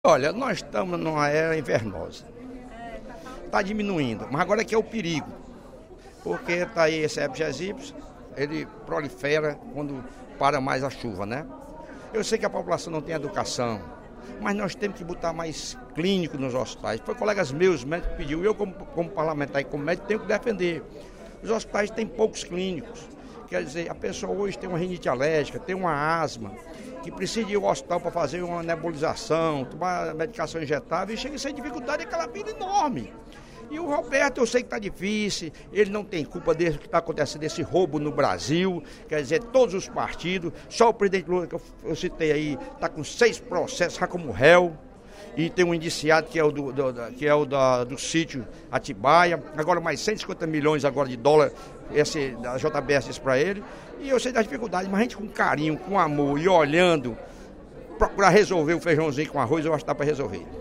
O deputado Lucílvio Girão (PP) solicitou, durante o primeiro expediente da sessão plenária desta quinta-feira (25/05), ao prefeito de Fortaleza, Roberto Cláudio, a contratação de mais médicos nas Unidades de Pronto Atendimento (UPAs).